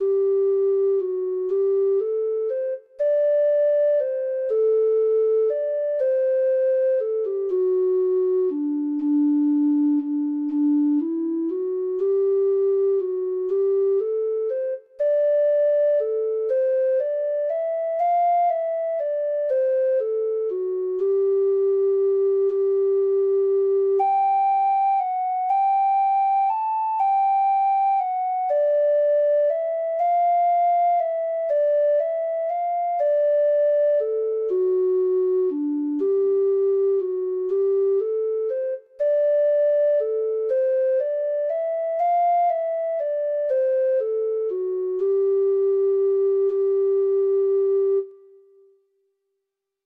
Traditional Music of unknown author.
Irish